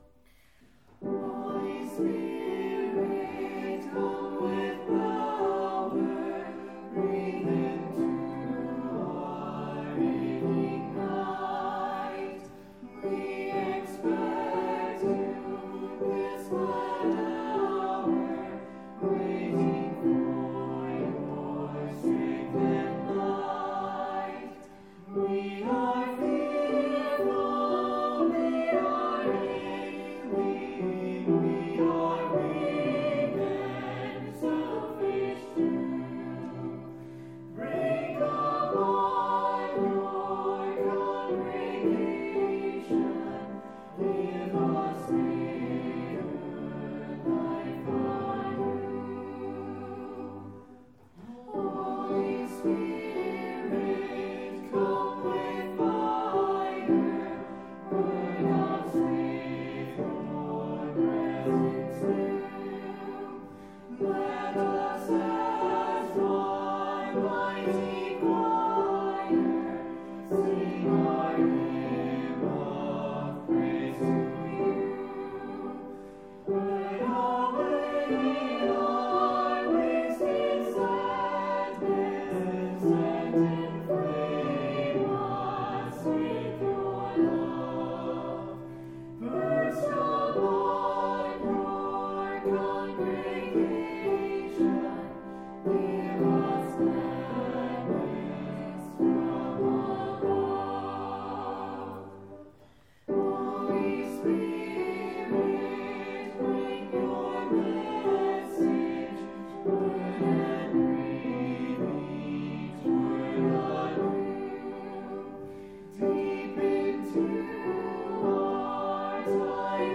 5.31.20-Hymns.mp3